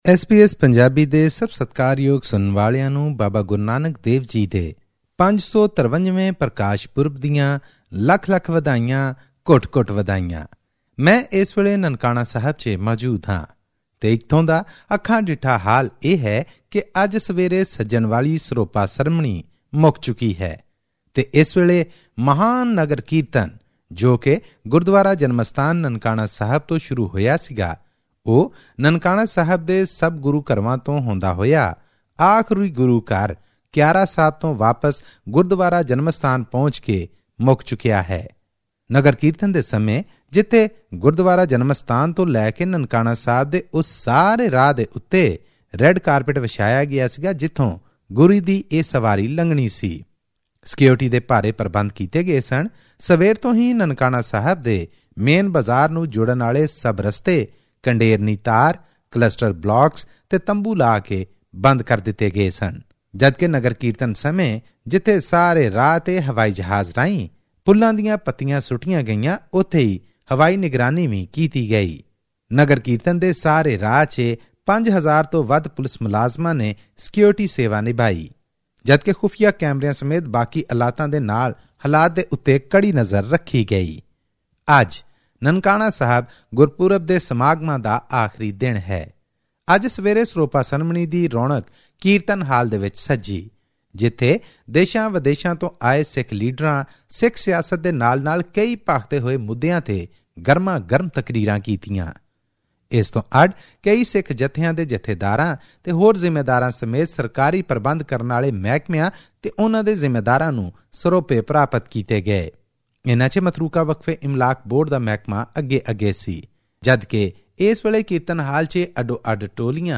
On the auspicious occasion of Sri Guru Nanak Gurpurab, here is exclusive coverage from Nankana Sahib
Thousands of Sikh pilgrims from around the world gathered at Nankana Sahib in Pakistan to commemorate Guru Nanak's 553rd birth anniversary. Here's a special report on the ongoing celebrations.